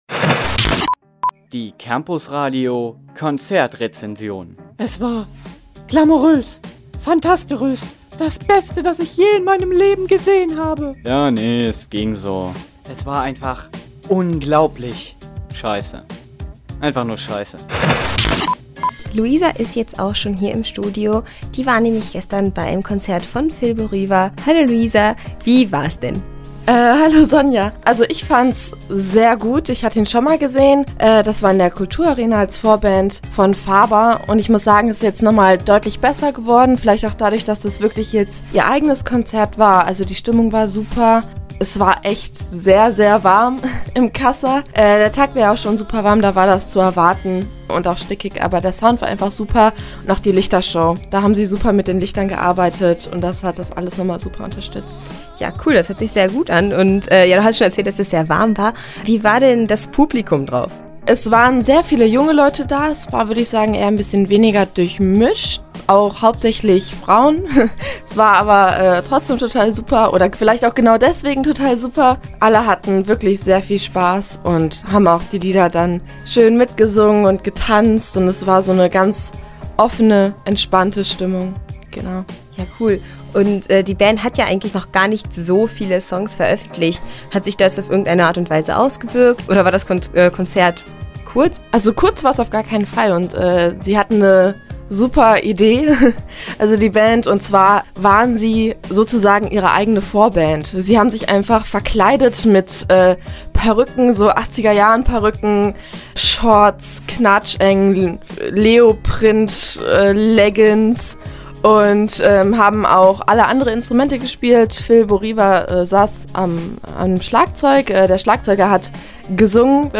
Konzertrezension: Fil Bo Riva – Campusradio Jena